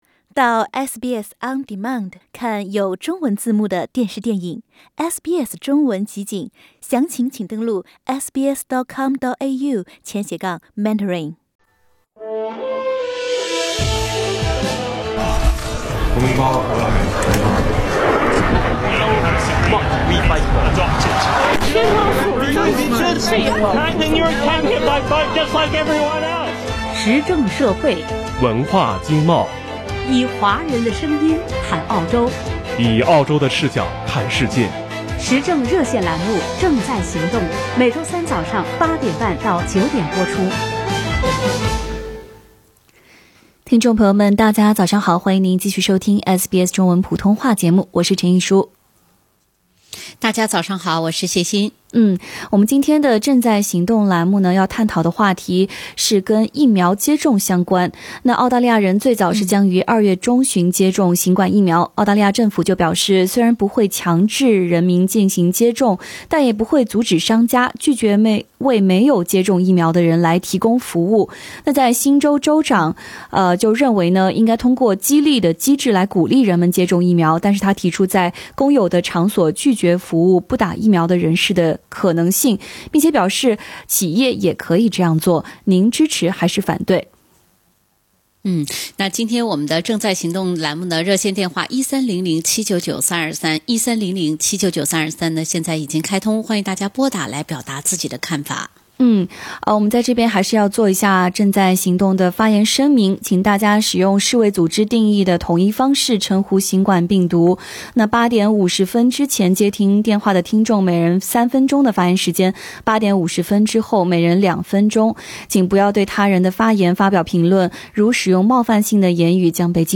参与节目的听众分享了自己的观点。